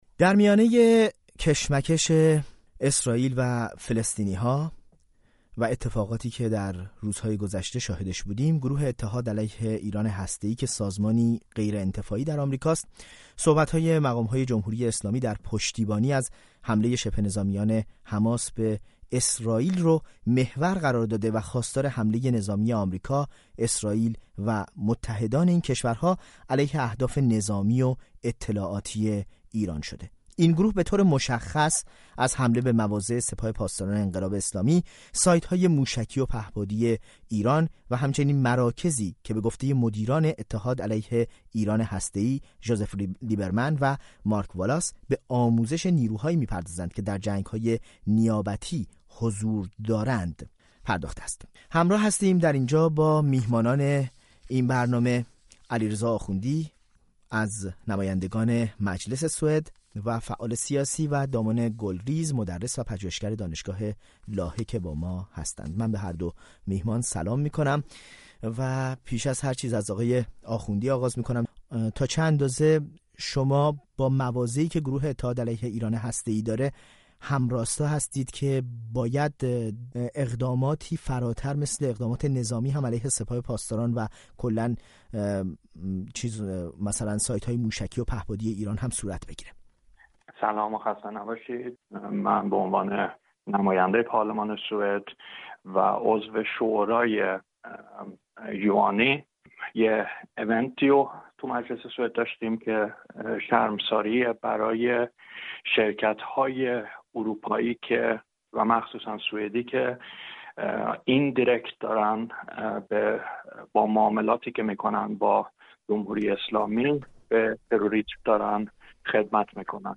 میزگرد: حملۀ هدفمند به ایران جمهوری اسلامی را تقویت‌ می‌کند یا تضعیف؟